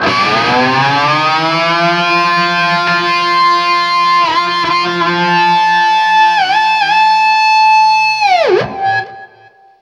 DIVEBOMB 9-R.wav